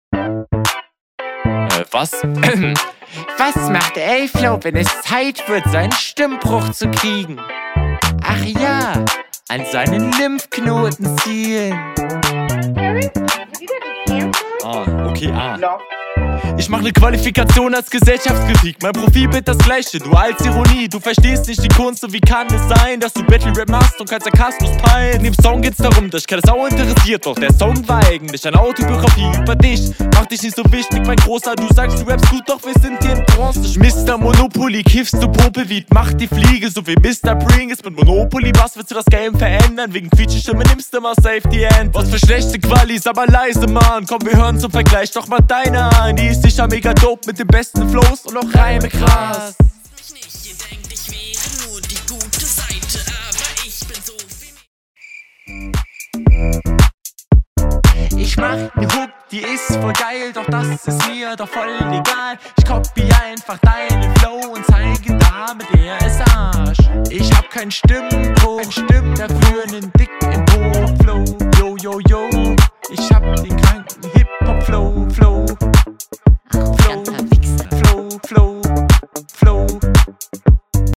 find paar Konter weird zum Besipiel das mit Bronze. flowlich bist auch ein größeres stück …